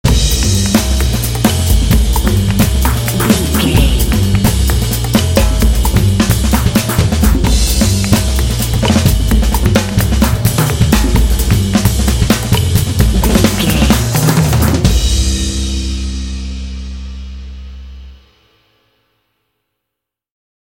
Mixolydian
groovy
percussion
double bass
jazz drums